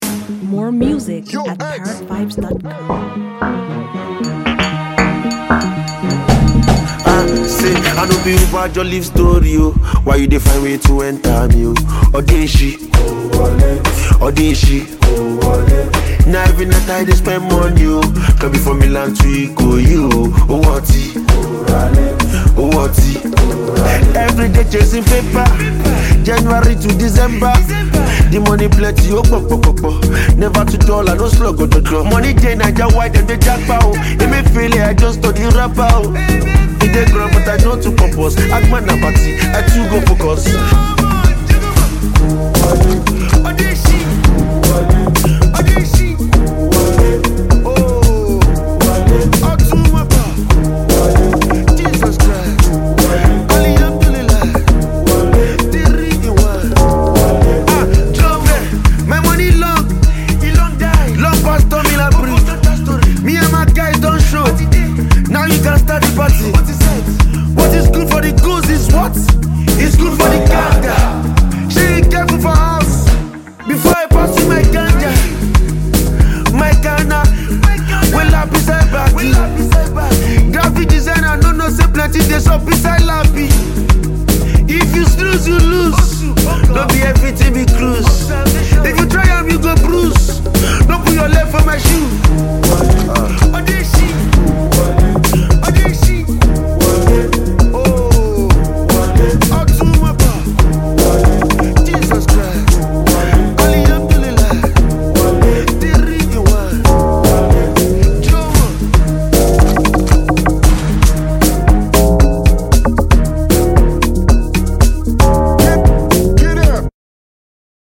Phenomenally talented Nigerian rapper and performer